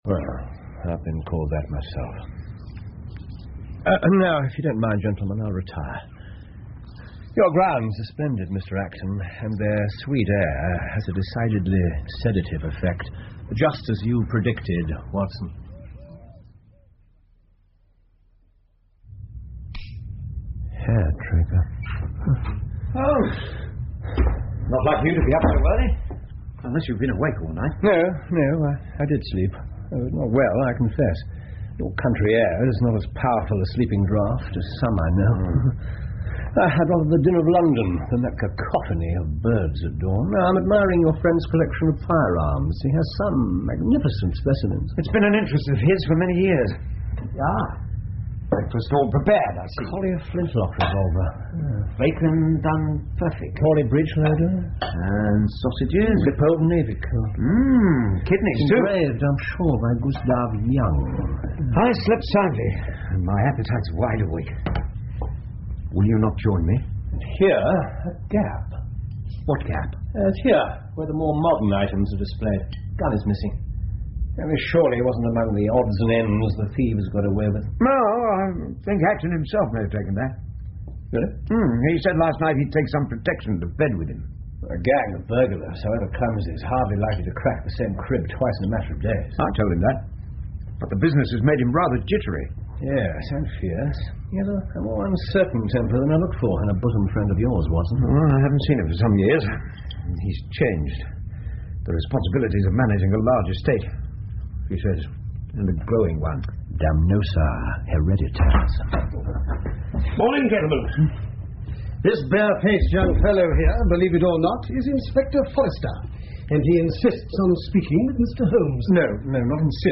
福尔摩斯广播剧 The Reigate Squires 3 听力文件下载—在线英语听力室